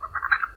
Add toucan sounds
sounds_toucan_03.ogg